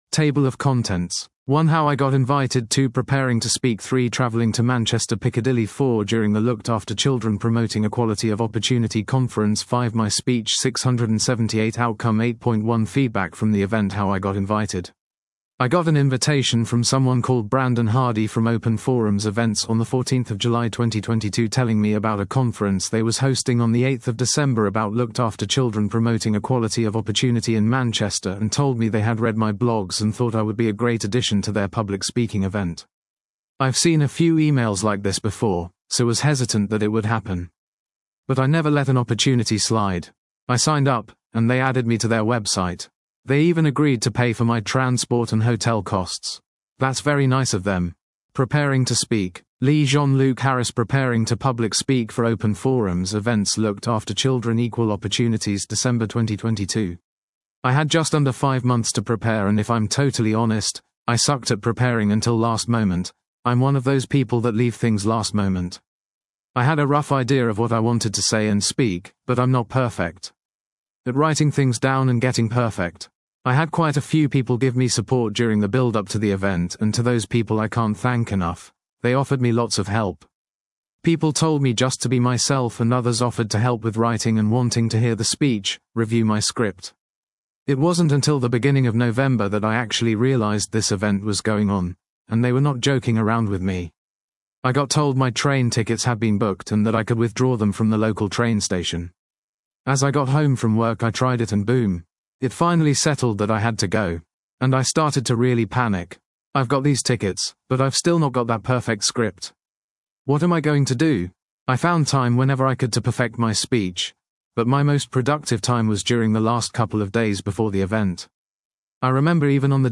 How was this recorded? my first public speech about growing up in care | Looked After Children Promoting Equality Of Opportunity Conference